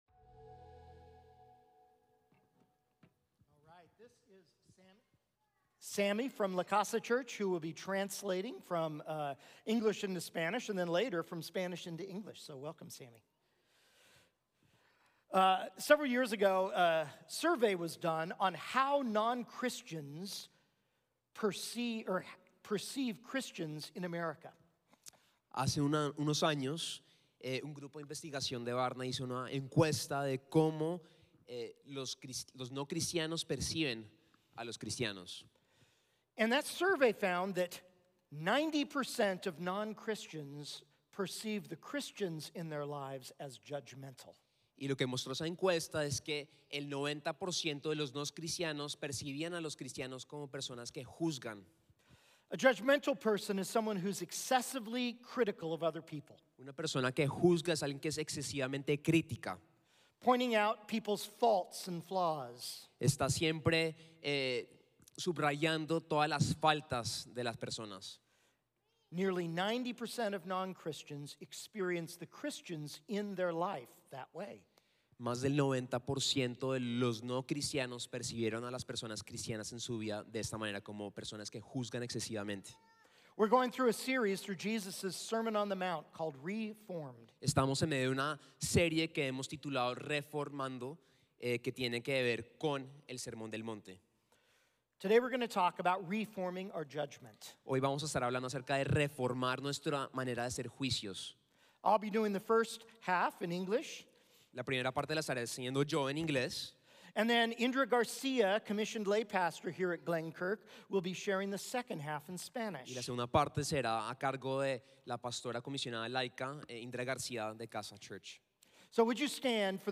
November 5, 2023 – Re-Forming our Judgement, Re-Formados en Nuestro Juicio (Message Only) – Glenkirk Church